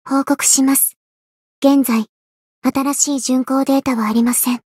灵魂潮汐-阿卡赛特-问候-不开心.ogg